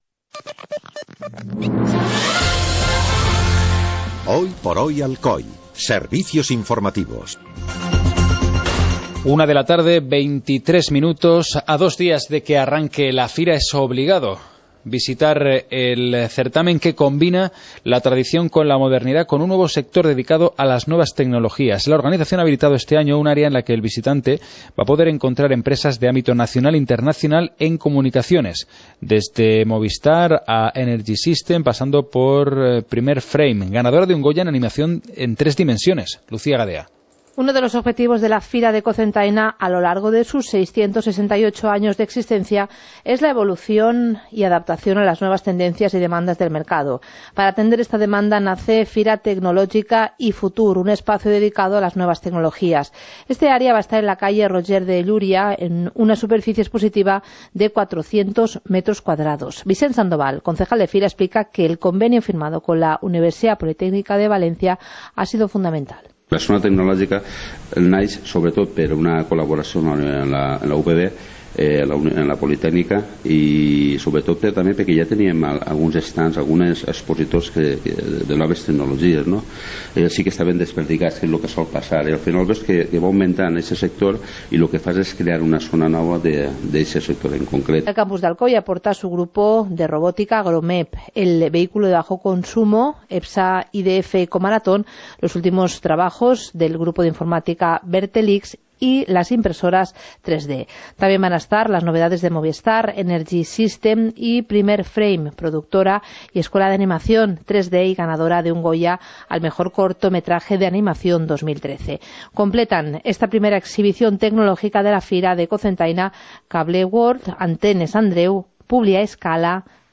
Informativo comarcal - miércoles, 29 de octubre de 2014